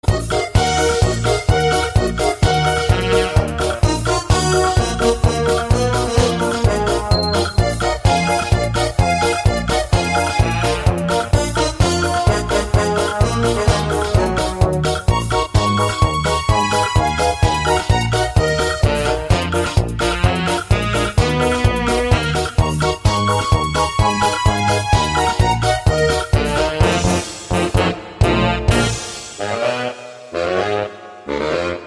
• Качество: 160, Stereo
Мелодия из мультфильма